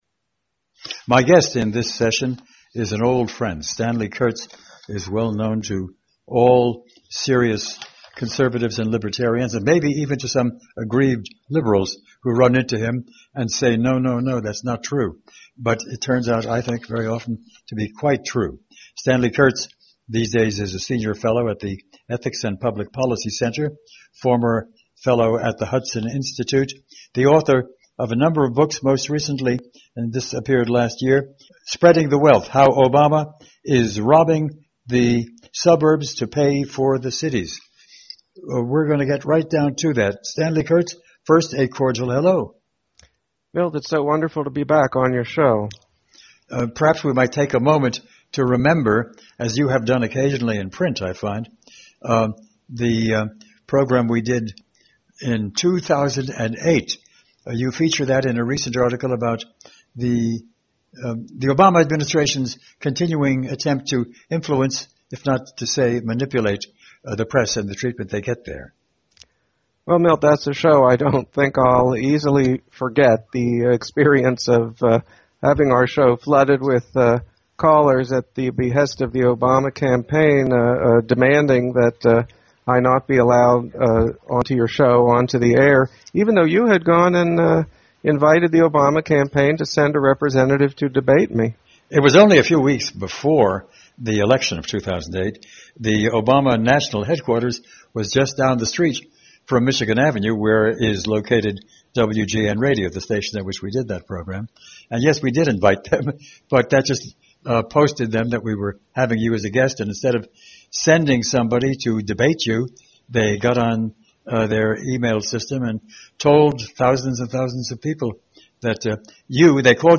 Milt Rosenberg is a daily podcast that features provocative and thought provoking discussion centered on the world of ideas.